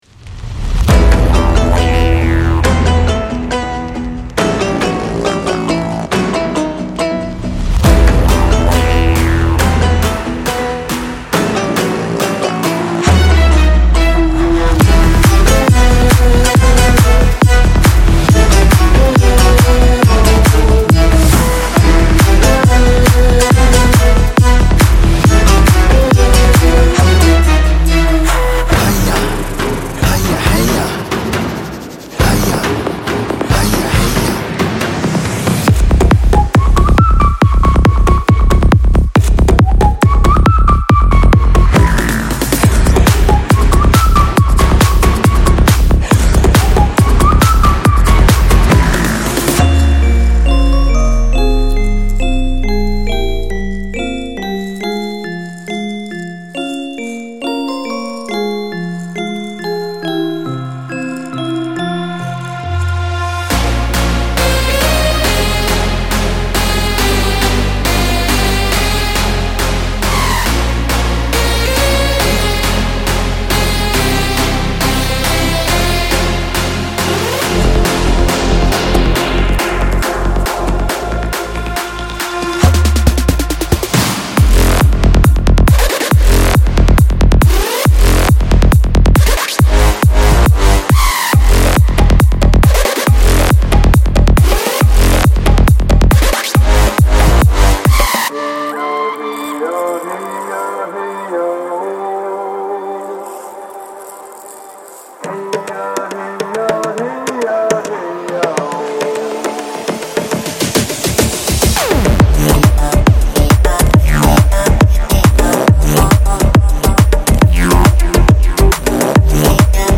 查看此功能强大的演示曲目，并在热销时获取副本！